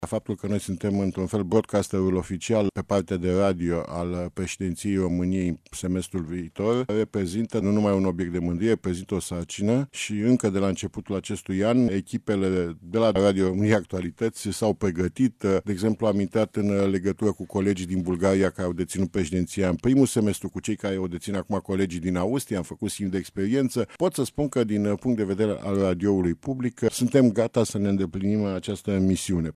Radio România se pregăteşte intens pentru una din misiunile de anul viitor, atunci când va fi difuzorul oficial al preşedinţiei României a Consiliului Uniunii Europene, a declarat de dimineaţă la Radio România Actualităţi, preşedintele director general al Societăţii Române de Radiodifuziune.